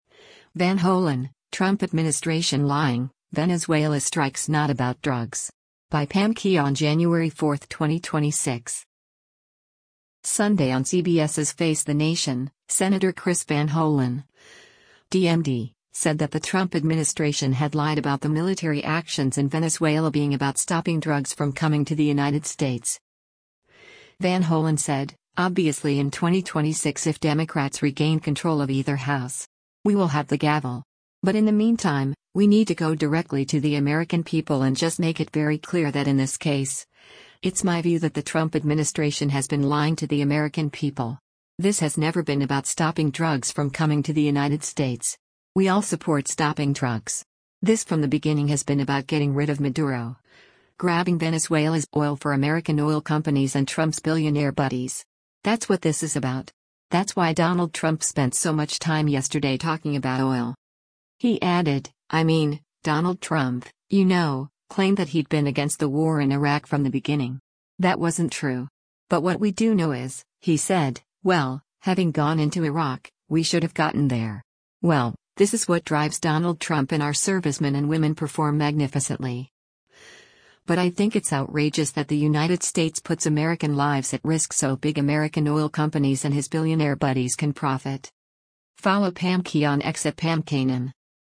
Sunday on CBS’s “Face the Nation,” Sen. Chris Van Hollen (D-MD) said that the Trump administration had lied about the military actions in Venezuela being about “stopping drugs from coming to the United States.”